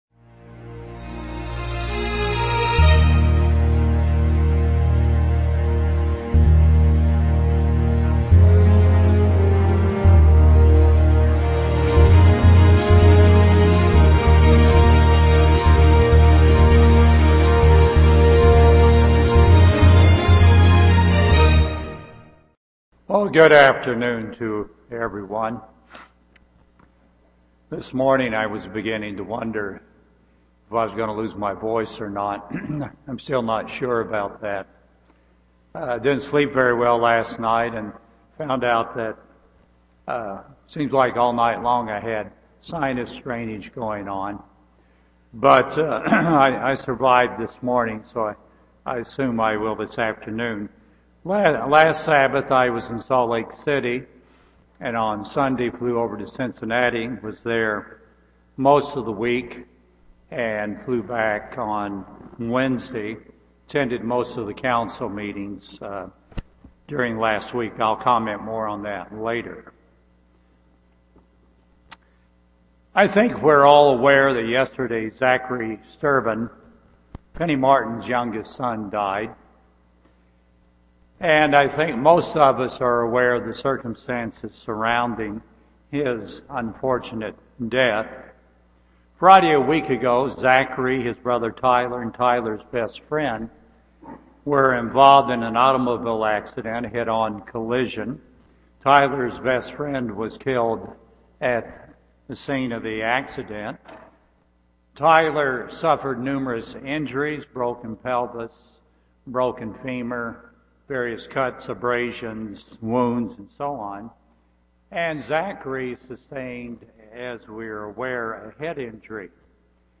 Revelation 21:4 UCG Sermon Transcript This transcript was generated by AI and may contain errors.